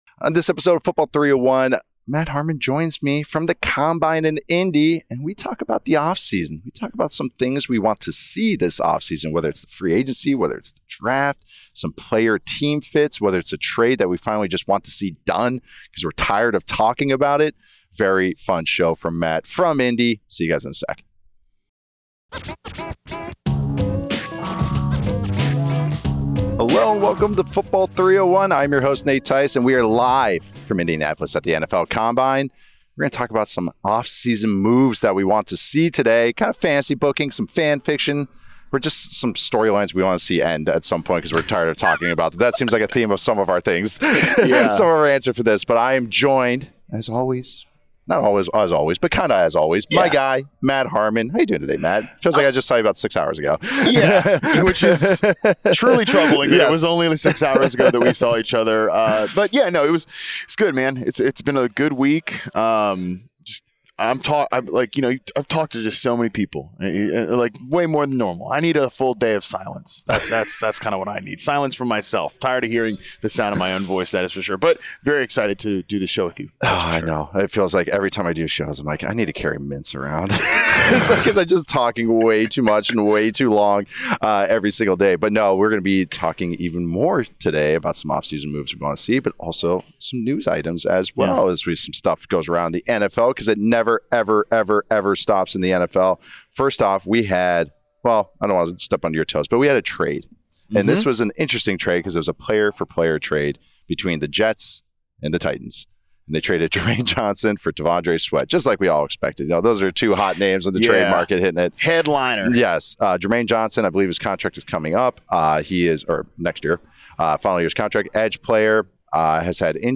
join forces LIVE in Indianapolis to reveal 9 big moves they’re rooting for this NFL offseason.